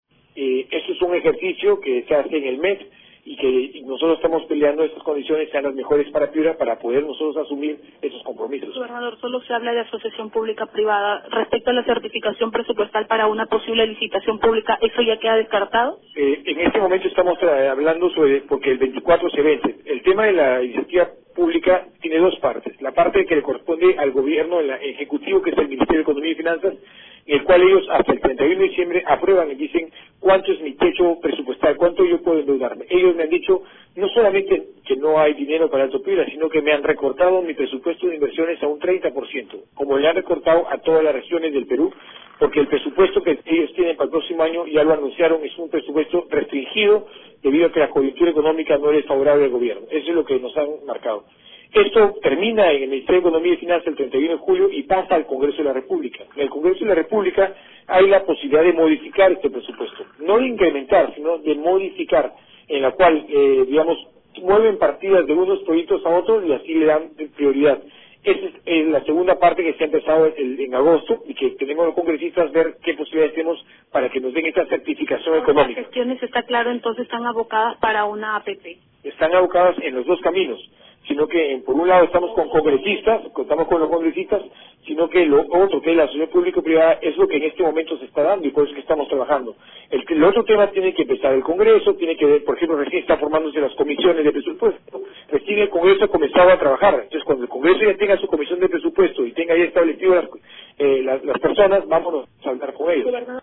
“Por un lado estamos coordinando con congresistas para sostener una reunión con las autoridades del gobierno central que permitan la certificación presupuestaria para el proyecto; y por otro lado, estamos viendo la posibilidad de una Asociación Público Privado” dijo en Cutivalú el gobernador regional.